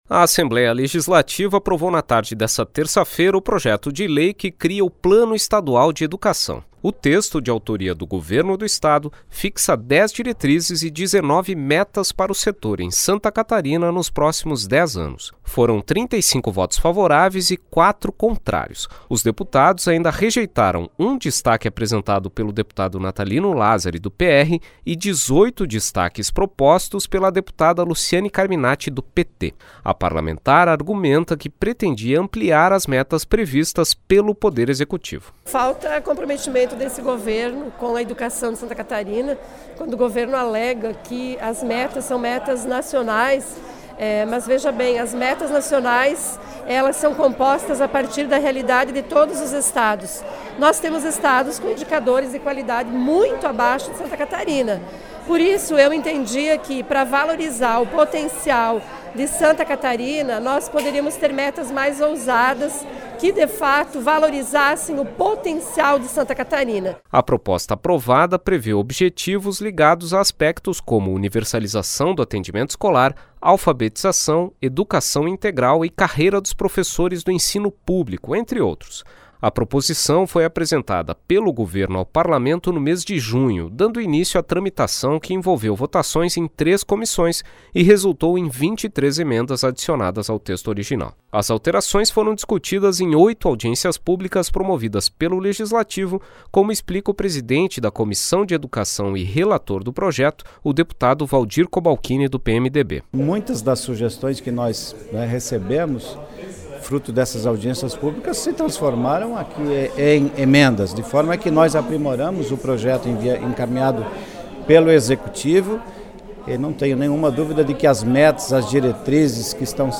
Entrevistas com: deputada Luciane Carminatti (PT); deputado Valdir Cobalchini (PMDB).